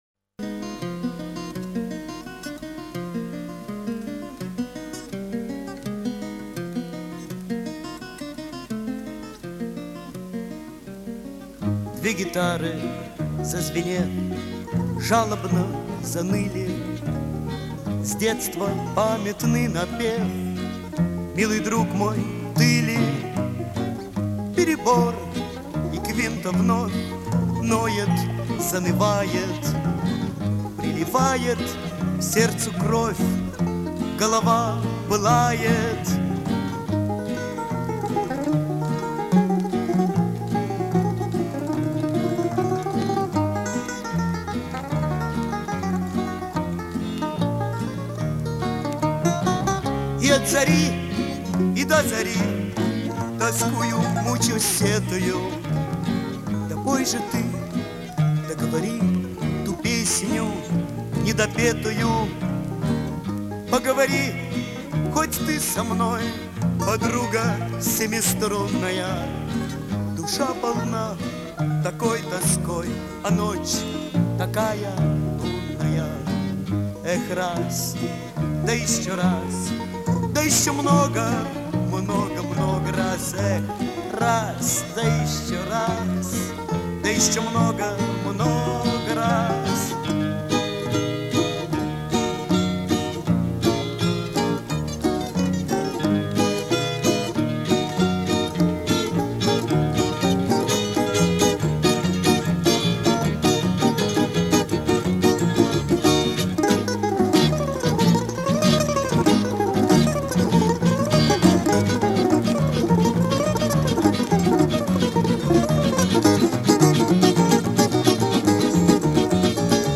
Жанр: романс, цыганская песня